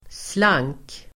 Uttal: [slang:k]